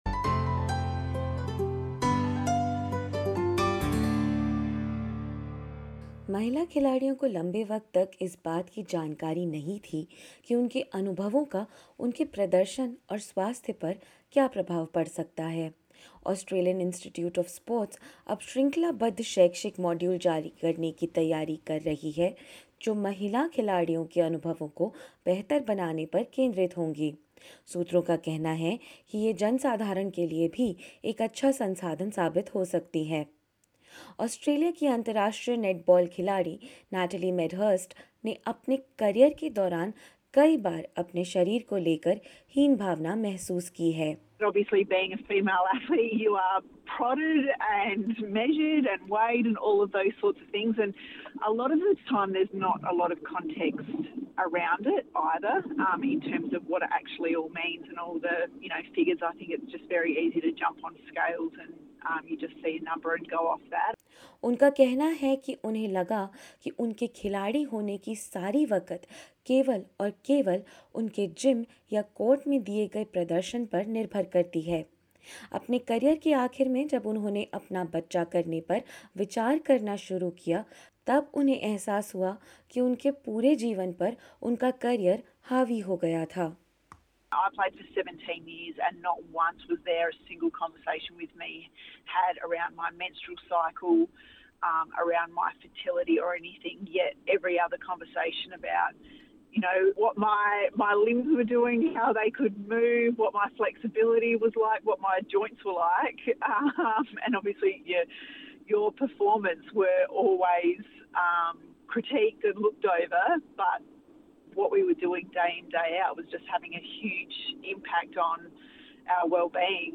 क्या हैं ये शैक्षिक मॉड्यूल, जानेंगे इस रिपोर्ट में।